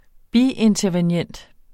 Udtale [ ˈbientʌvenˌjεnˀd ]